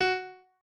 pianoadrib1_44.ogg